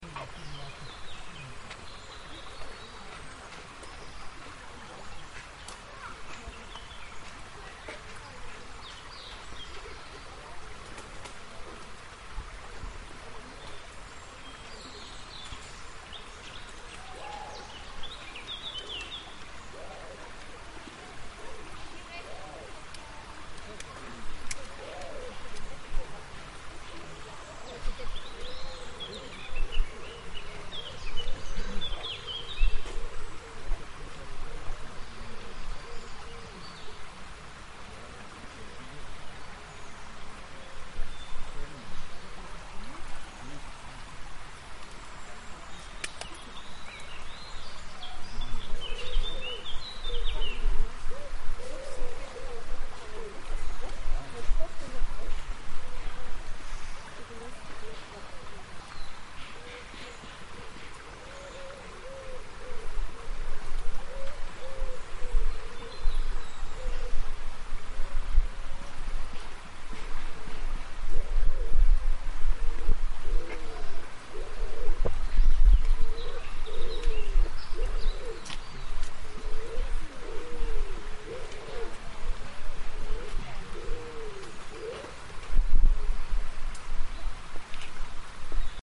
Sonds of the Alhambra (2). Recorded by iesalbayzin